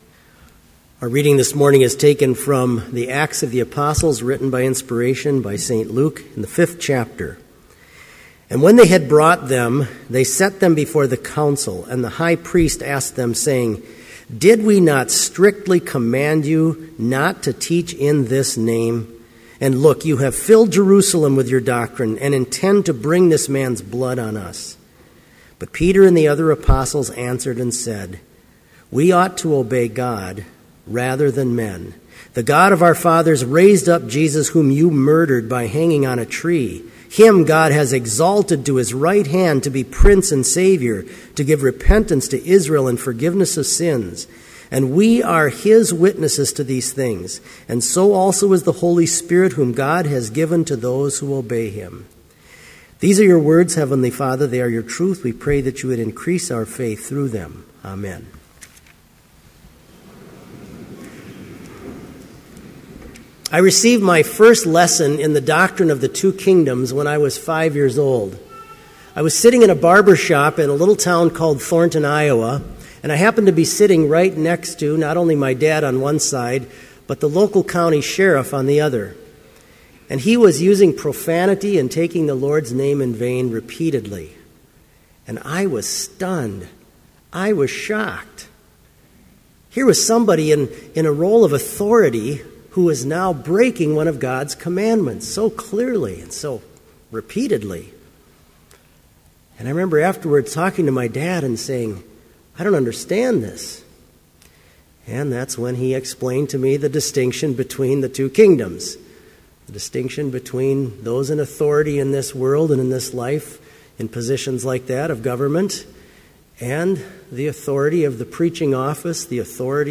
Complete service audio for Chapel - November 8, 2013
Homily